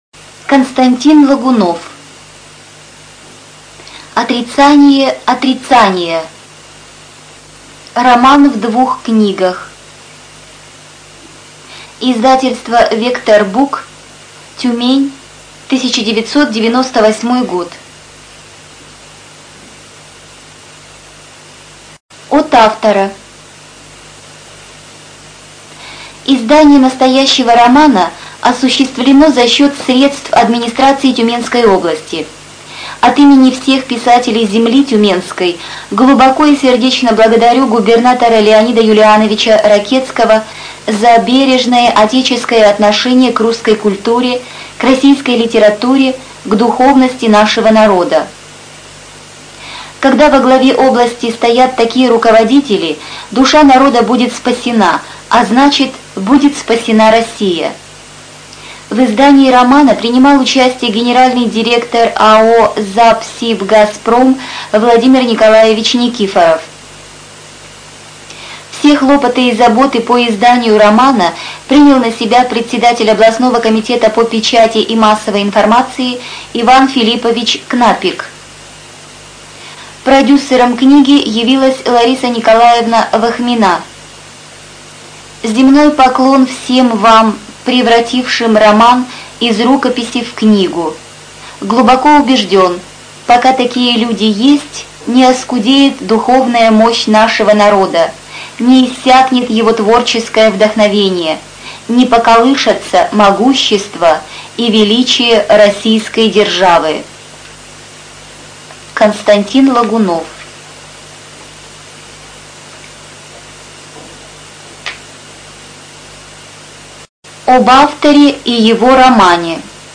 Студия звукозаписиТюменская областная библиотека для слепых